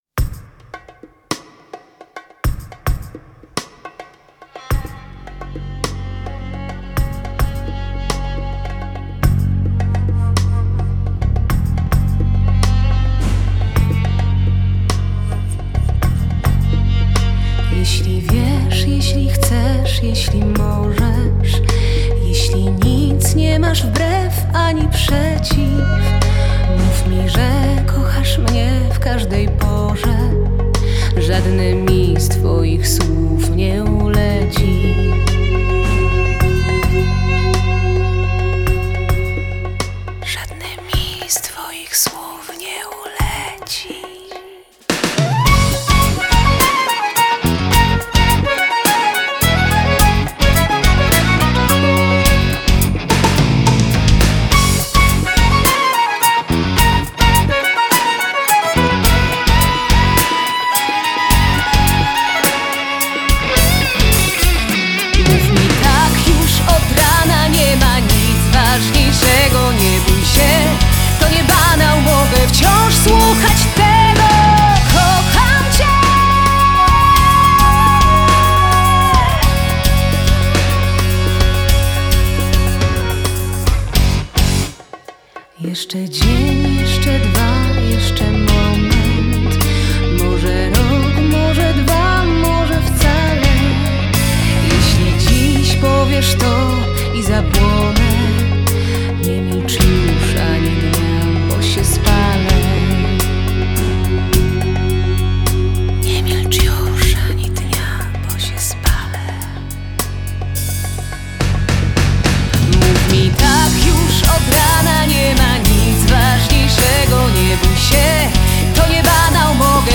Genre: Folk-Rock